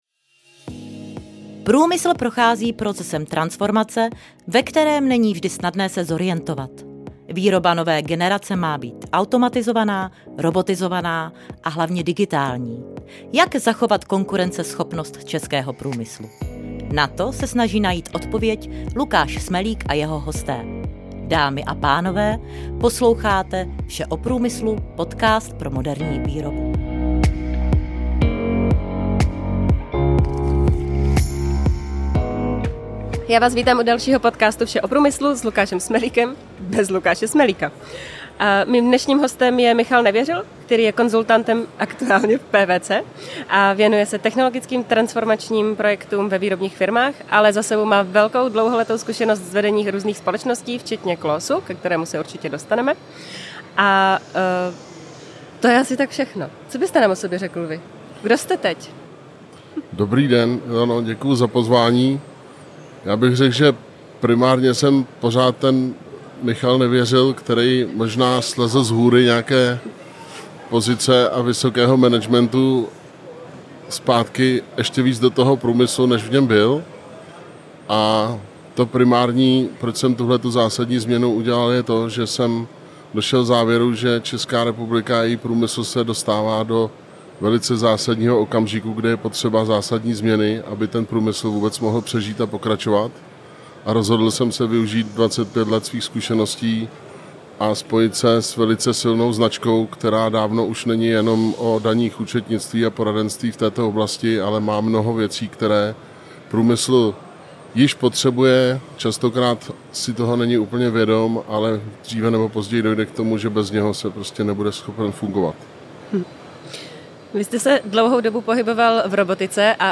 Do dalšího z rozhovorů v rámci série vysílané z MSV 2025